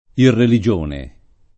[ irreli J1 ne ]